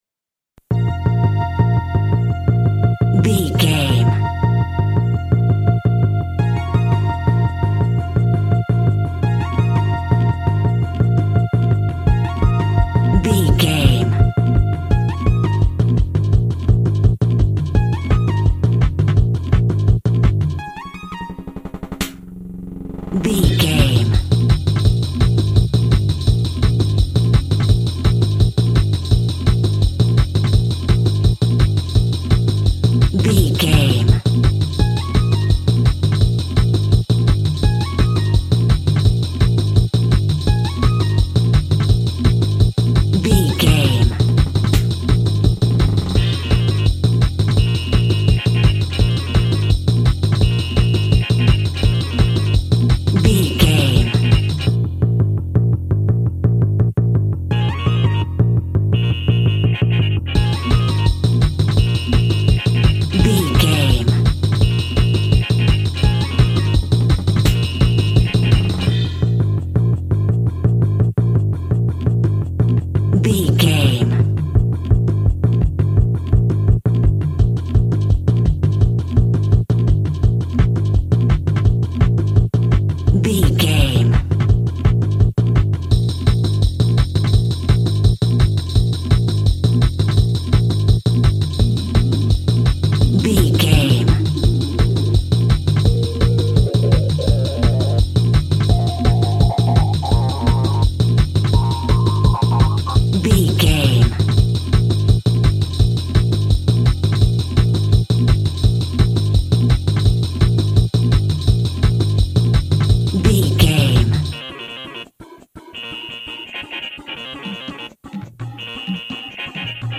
Racing Game Trance Music.
Aeolian/Minor
B♭
Fast
energetic
futuristic
hypnotic
frantic
drum machine
synthesiser
electric guitar
techno
synth lead
synth bass
Synth pads